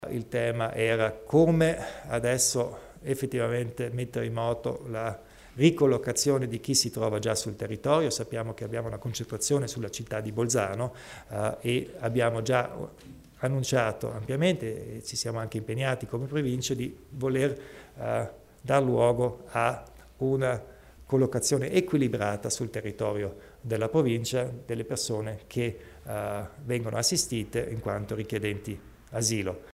Il Presidente Kompatscher spiega le strategie in tema di gestione profughi